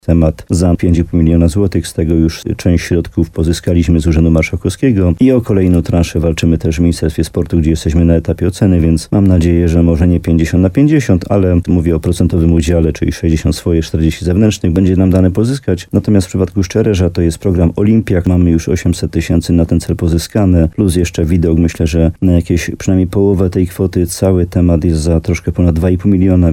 Jak mówi wójt gminy Łącko Jan Dziedzina, w przypadku Czarnego Potoku, przy budowa sali nastąpi też rozbudowa szkoły o dodatkowe zaplecze i sale lekcyjne.